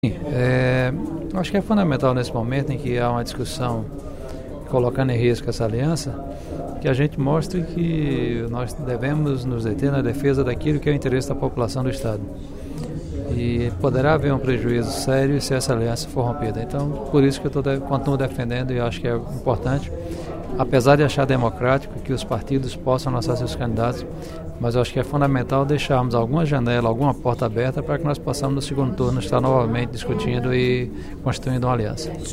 Em pronunciamento nesta quinta-feira (14/06) na Assembleia Legislativa, o deputado Professor Pinheiro (PT) defendeu a manutenção da aliança entre PT e PSB, com vistas às eleições municipais deste ano.